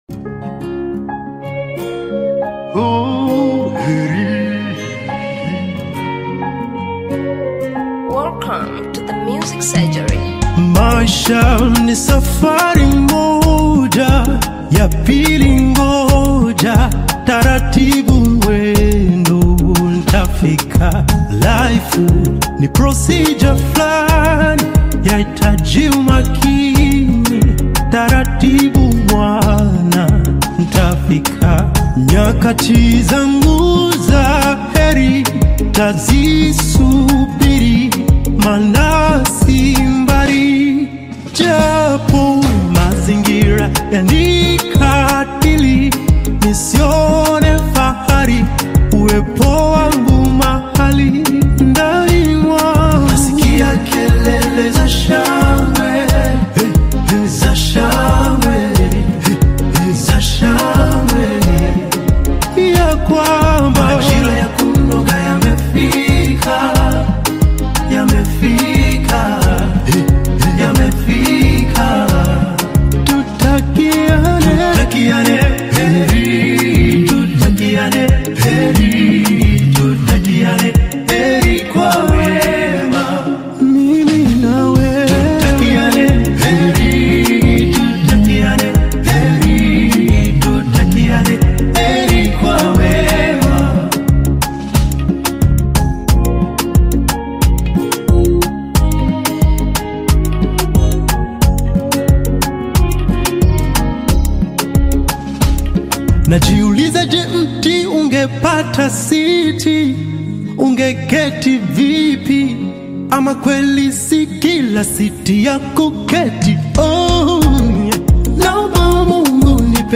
Gospel artist, singer and songwriter from Tanzania
Gospel song